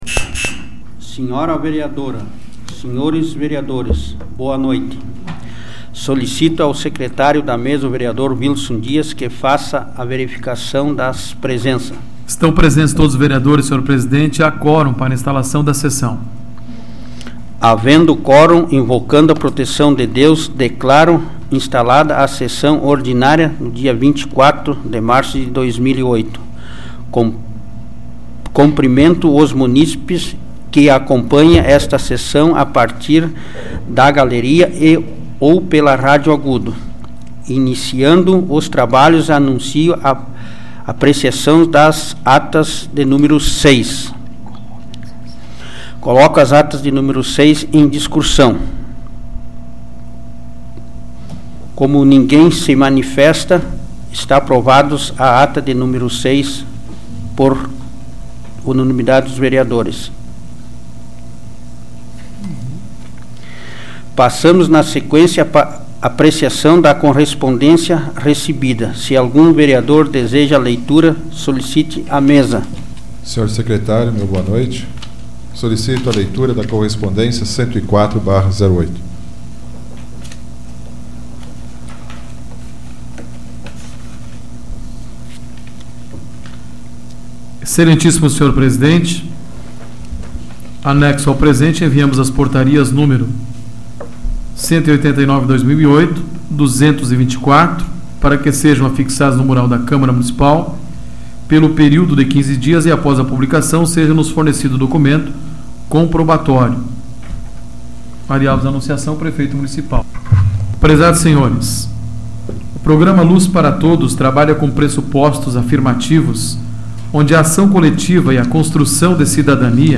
Áudio da 115ª Sessão Plenária Ordinária da 12ª Legislatura, de 24 de março de 2008